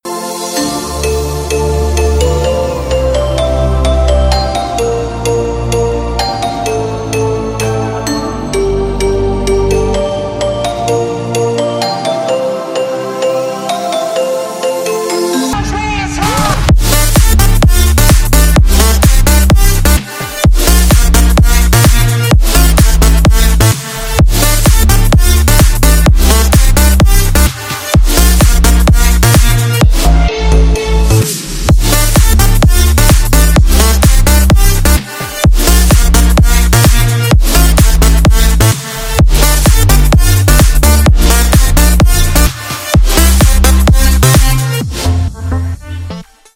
• Качество: 320, Stereo
мужской голос
dance
Electronic
EDM
космические
house
electro house
звонкие